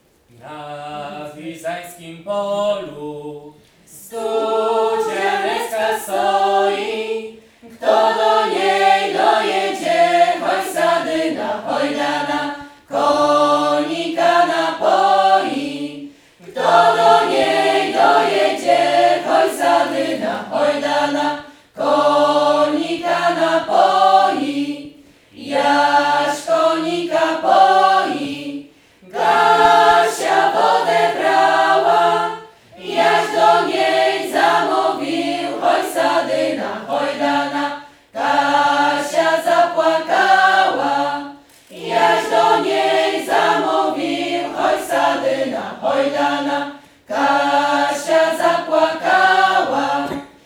W niedzielę, 20 września, w Sali Kameralnej odbył się koncert tradycyjnych pieśni w wykonaniu zespołów ludowych, przy współudziale osób kultywujących ten gatunek muzyki.
Zabrzmiały zatem tradycyjne pieśni z naszego regionu – tęskne i radosne, mówiące o uczuciach, czasem szczęśliwych, czasem nietrafnie ulokowanych, o codziennym życiu, trudach pracy na roli, radościach i smutkach, ale też pięknie krajobrazu.
Śpiewacy z Wiżajn z uczestnikami warsztatów:
jezioranki.wav